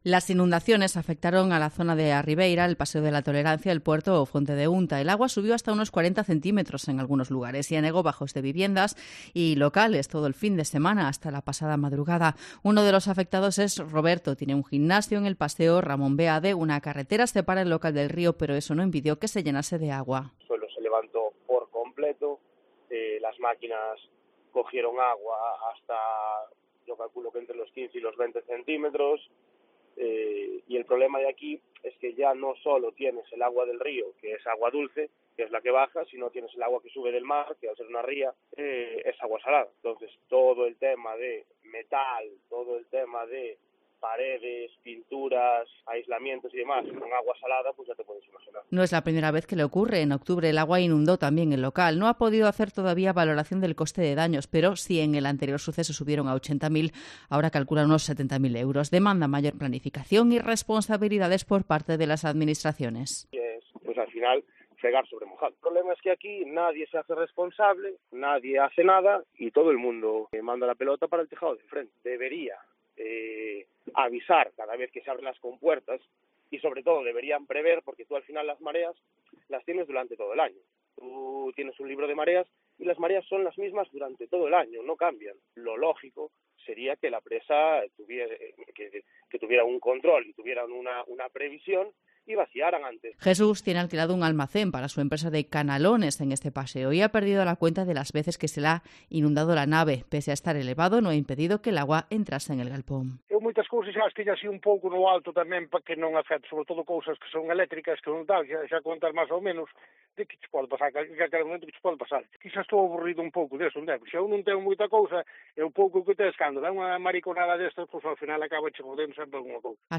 Inundaciones en Betanzos: hablamos con afectados y la alcaldesa, María Barral